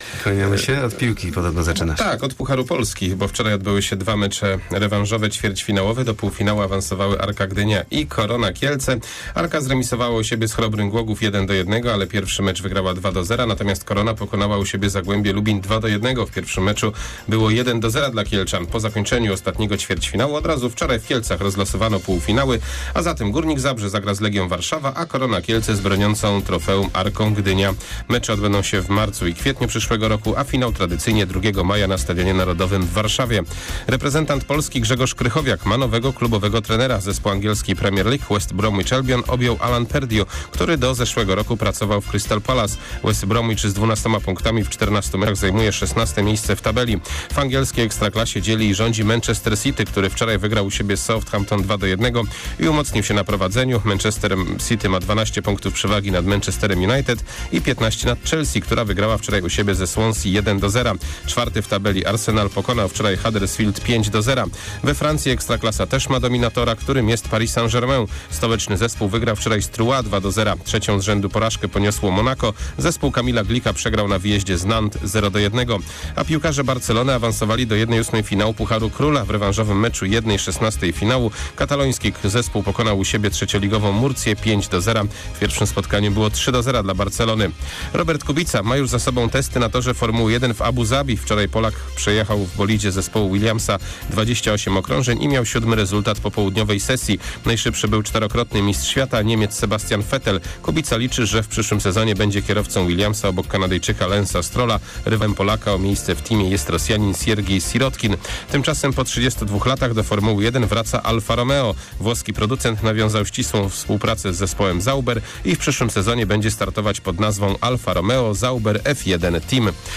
30.11 serwis sportowy godz. 7:45